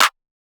clap 21 (from my kit).wav